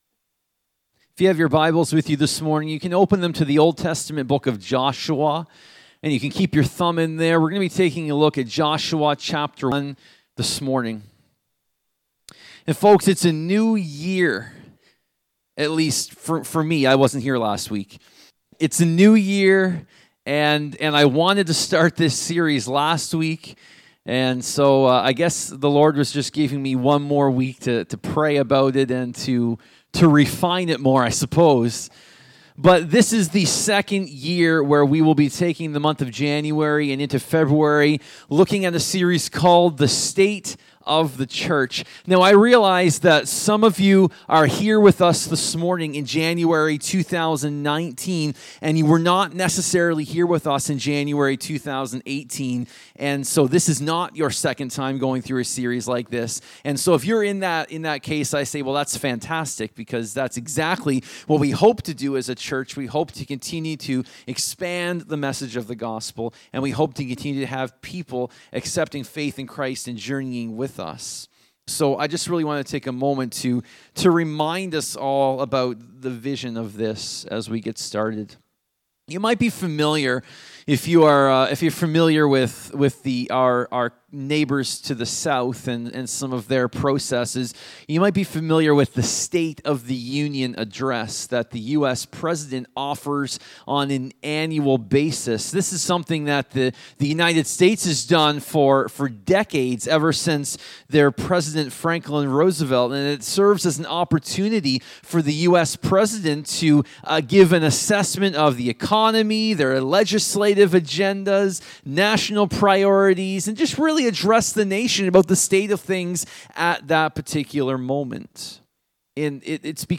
Sermons | Evangel Assembly